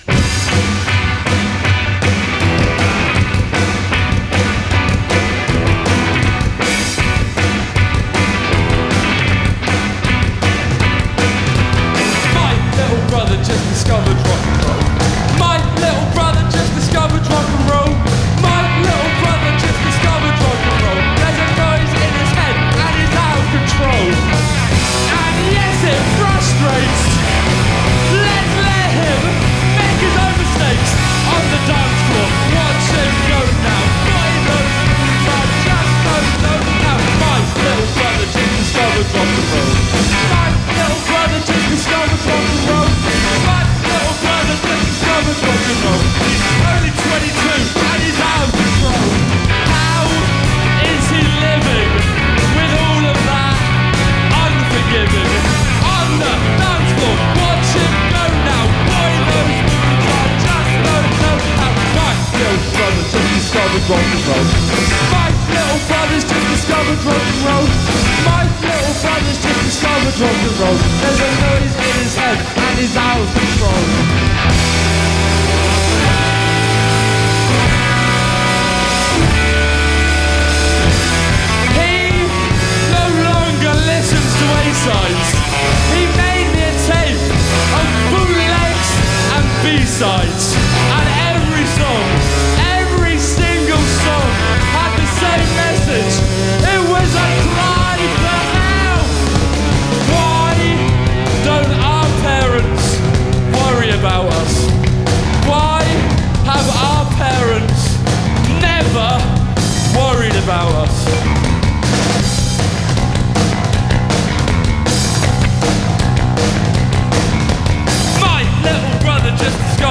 straightforward guitar rock setup
enthusiastic sprechgesang-style vocal delivery